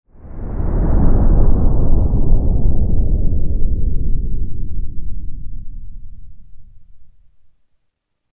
Deep Rumbling Transition Whoosh Sound Effect
Description: Deep rumbling transition whoosh sound effect. This low airy slow whoosh sound effect creates a smooth and atmospheric transition. It works great for slowing down scenes, dramatic intros, or logo reveals.
Deep-rumbling-transition-whoosh-sound-effect.mp3